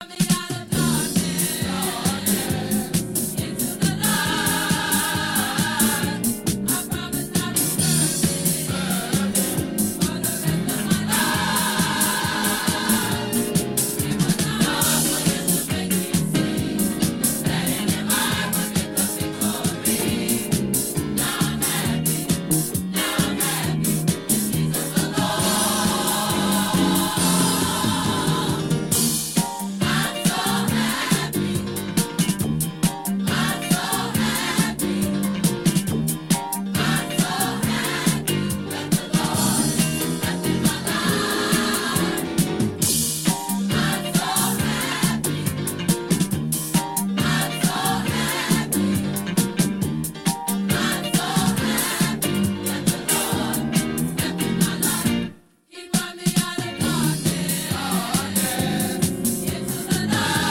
Gospel Disco.